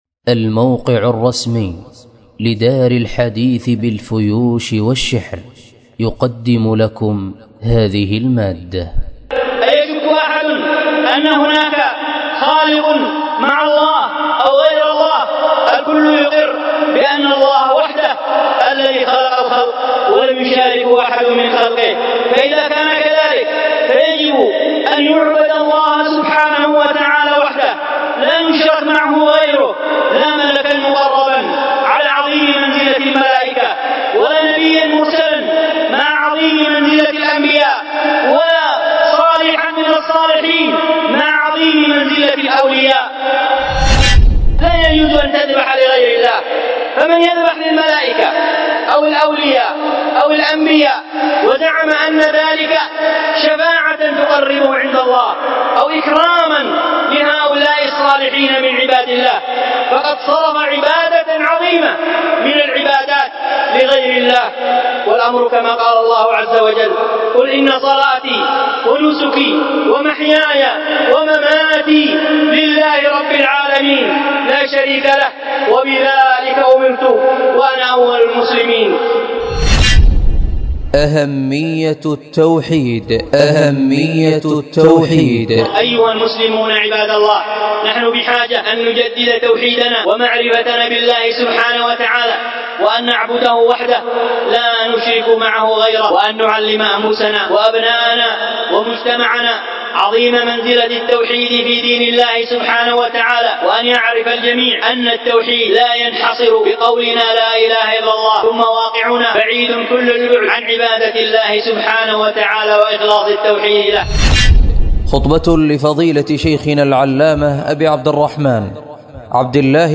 الخطبة
والتي كانت بدار الحديث بالفيوش